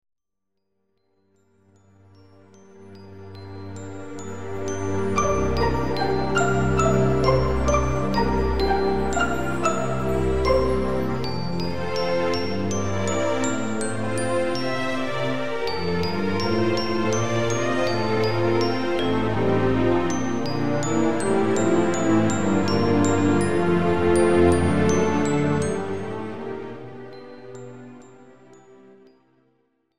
This is an instrumental backing track cover.
• Key – A# / Bb
• Without Backing Vocals
• No Fade